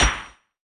hitSmallOther.wav